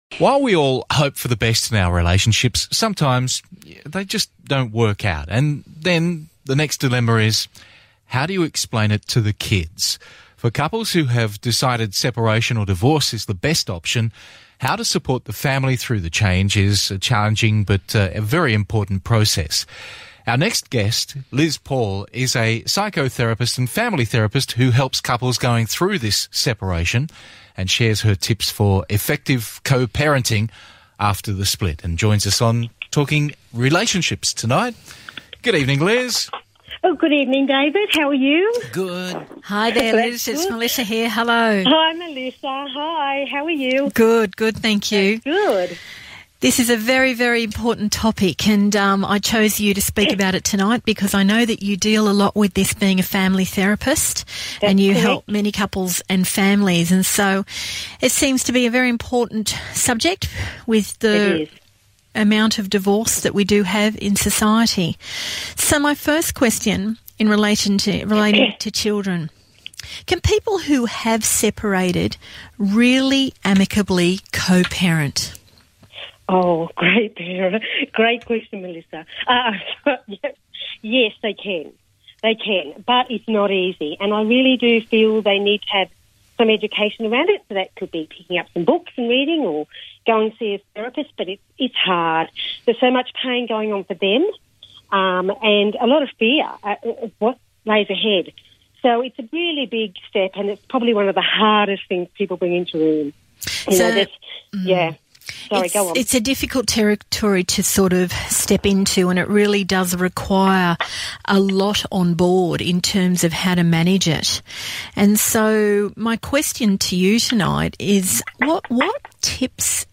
Radio interview: Can people amicably co-parent after a divorce?